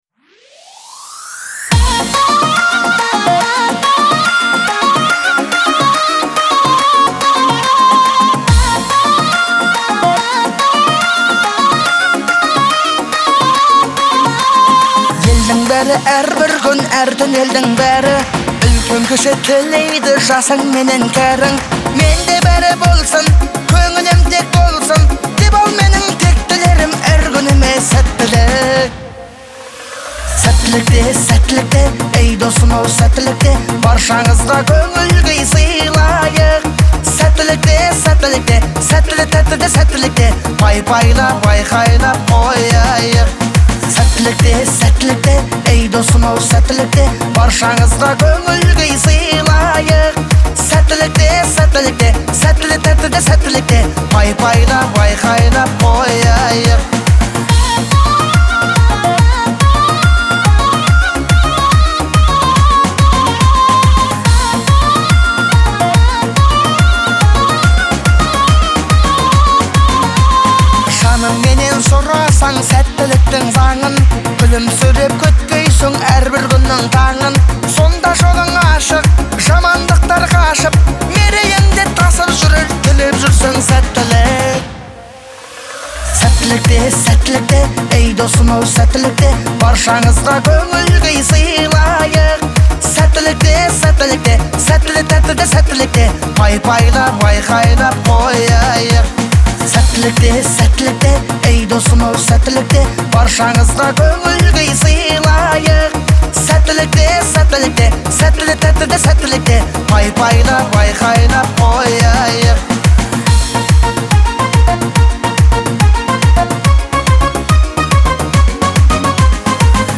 это яркий представитель казахской поп-музыки